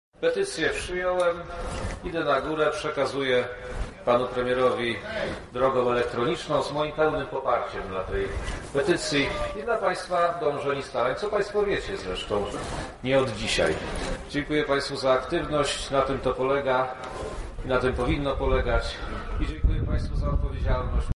Na manifestacji głos zabrała